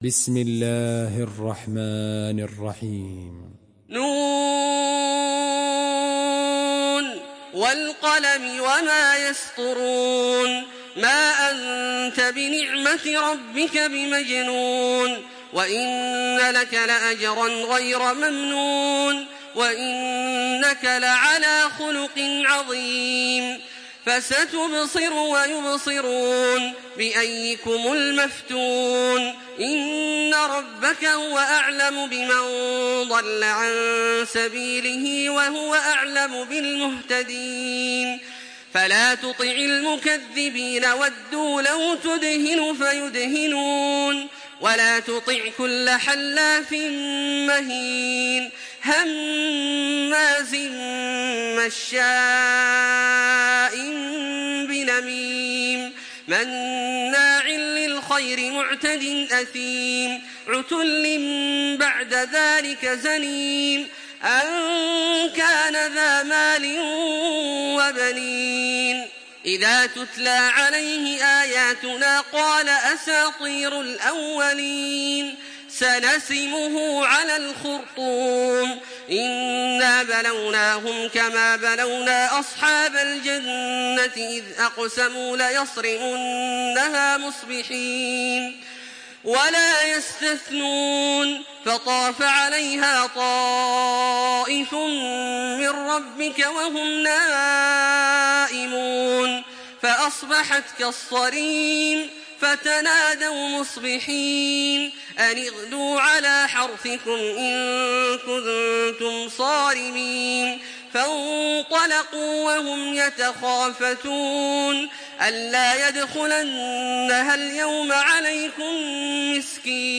Surah Kalem MP3 by Makkah Taraweeh 1426 in Hafs An Asim narration.
Murattal Hafs An Asim